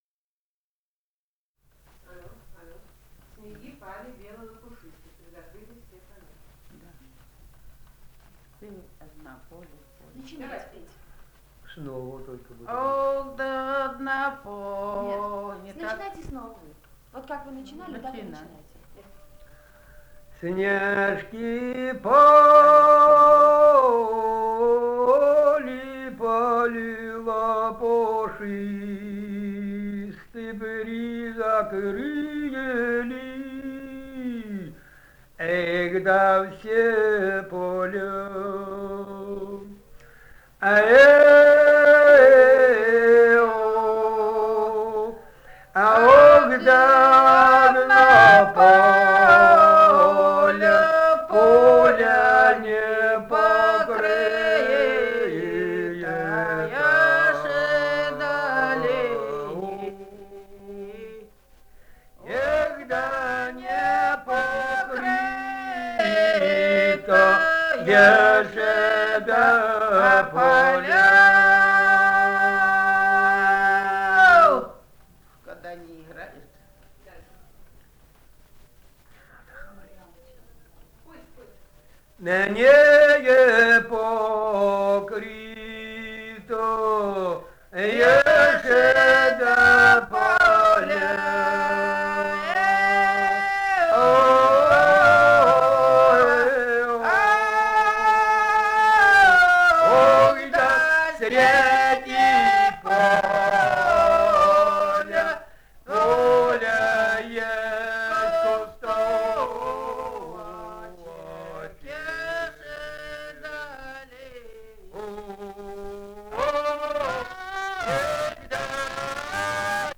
Этномузыкологические исследования и полевые материалы
Алтайский край, с. Михайловка Усть-Калманского района, 1967 г. И1001-02